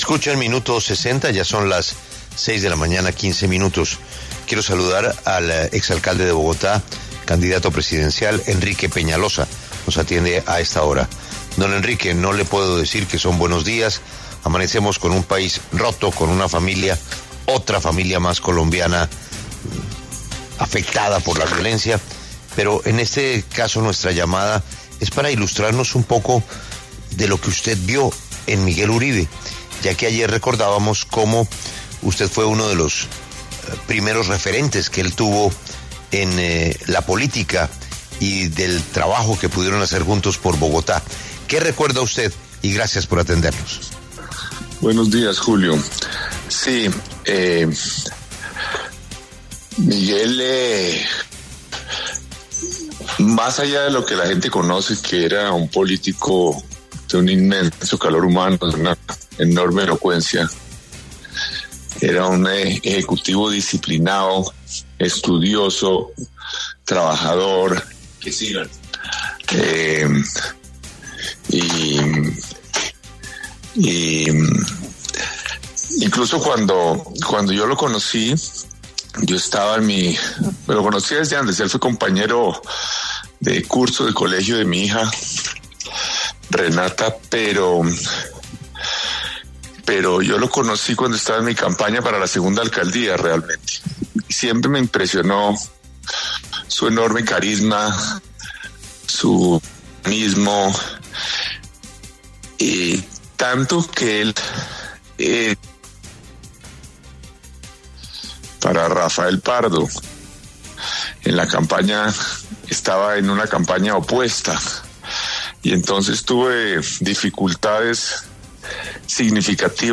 Enrique Peñalosa, exalcalde de Bogotá y candidato presidencial, habló en La W, con Julio Sánchez Cristo, sobre la muerte de Miguel Uribe, senador y precandidato y, además, persona con la que compartió en la Alcaldía de la capital del país, pues Uribe Turbay en 2016 fue su secretario de Gobierno.